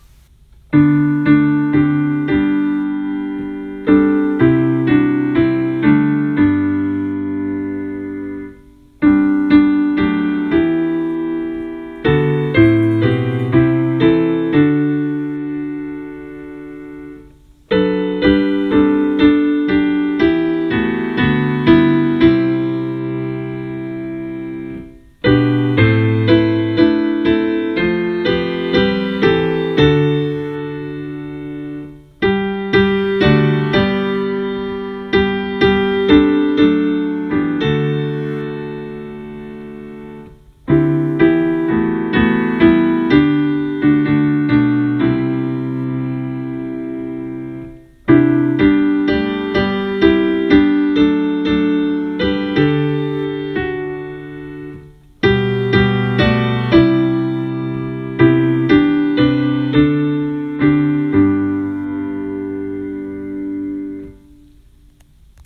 This is a hymn of praise and proclamation based on the outline of four of God’s attributes: wisdom, grace, power, and glory.